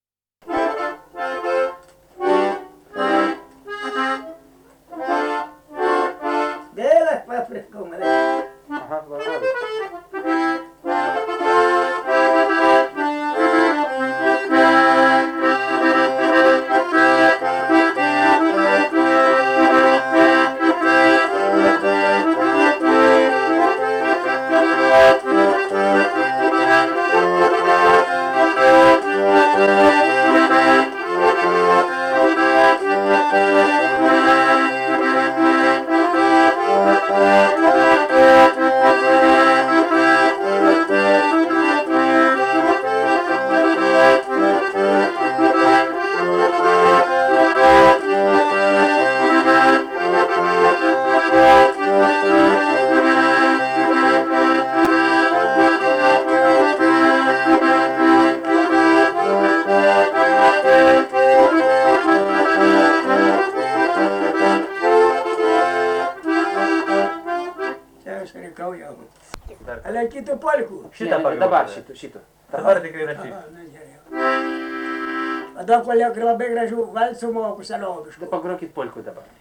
šokis